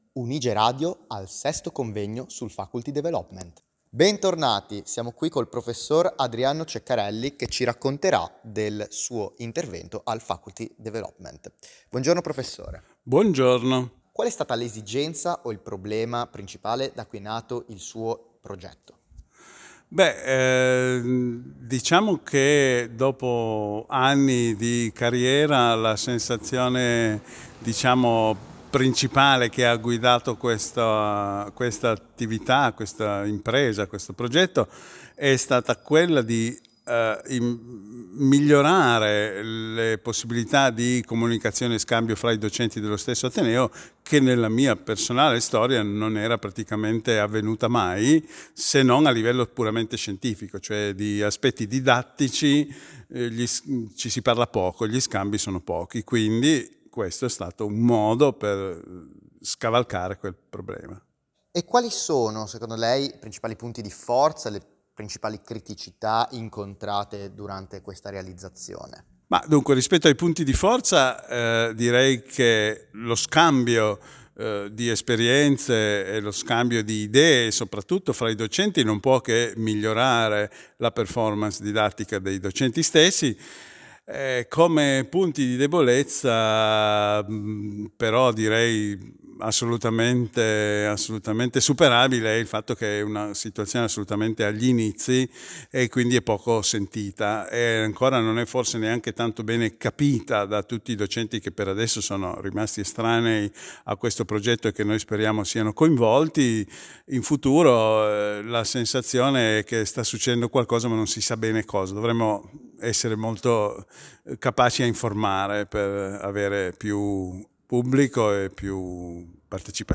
Faculty development - 6° convegno nazionale